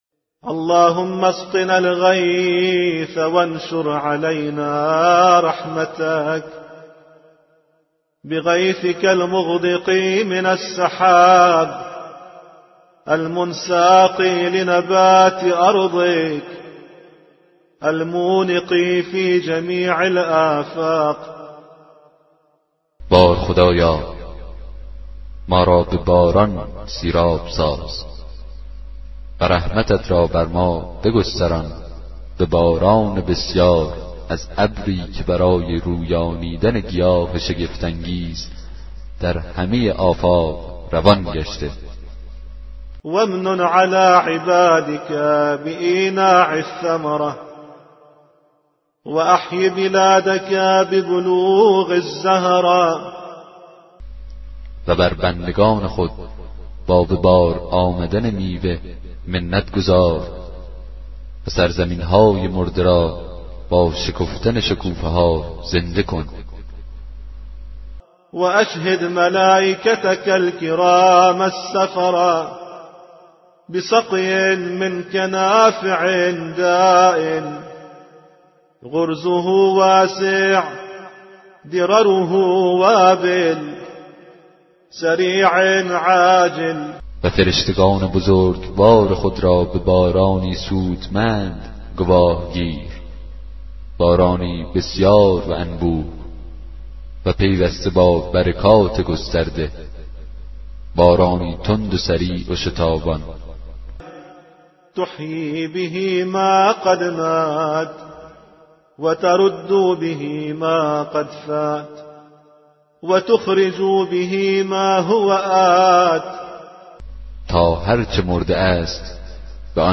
کتاب صوتی دعای 19 صحیفه سجادیه